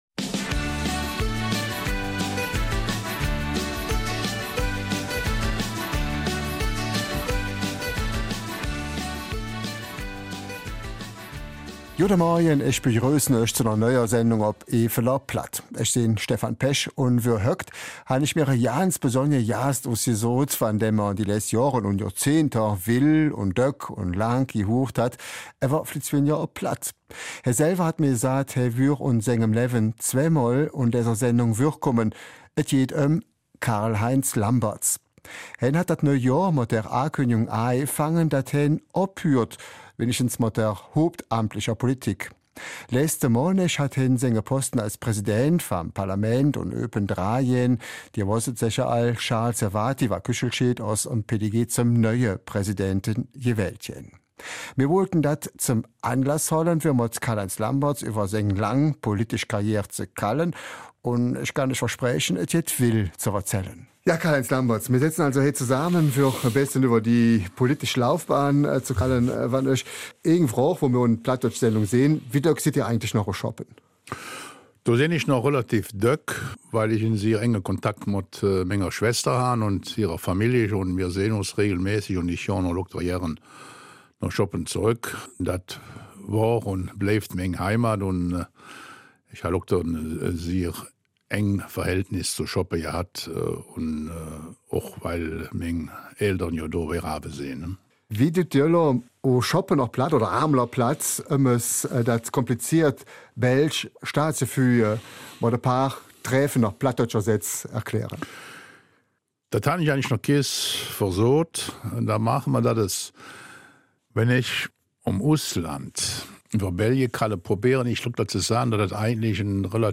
Eifeler Mundart: Zeitenwende - Karl-Heinz Lambertz beendet seine hauptamtliche politische Karriere